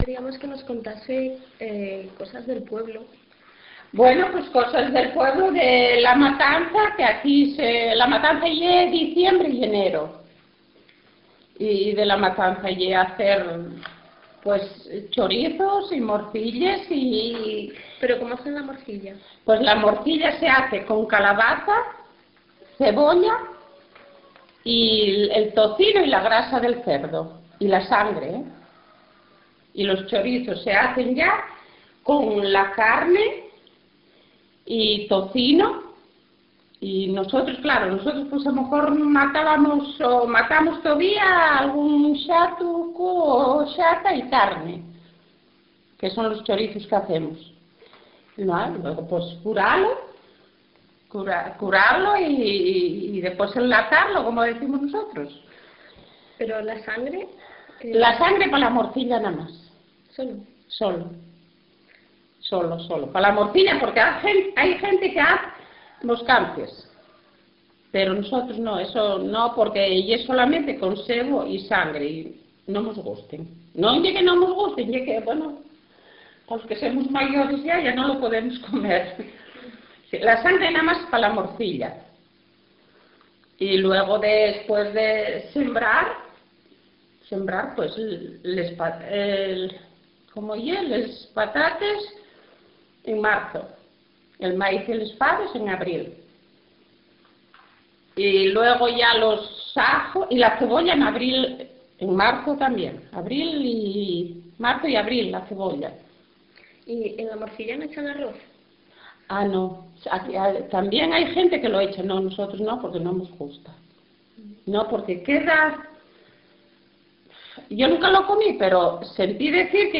Locality La Cruz - Lieres (Siero)
mujer